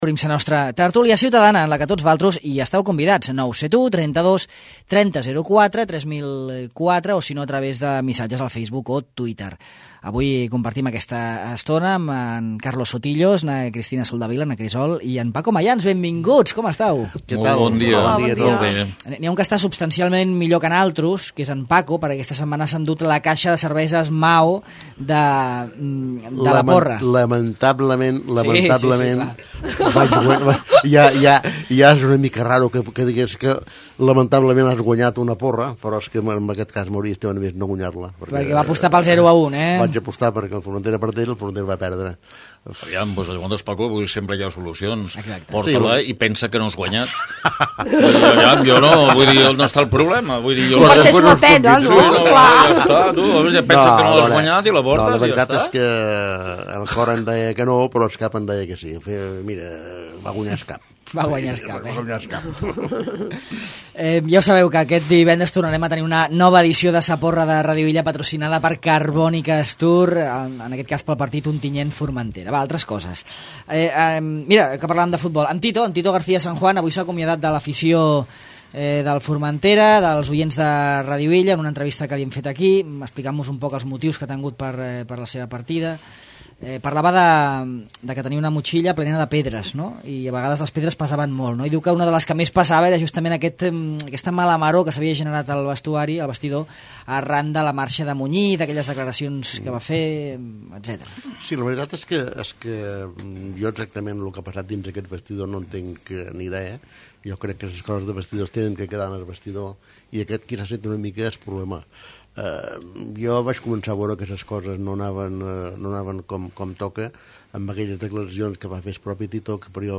La tertúlia del dimarts parla del consum de cànnabis en joves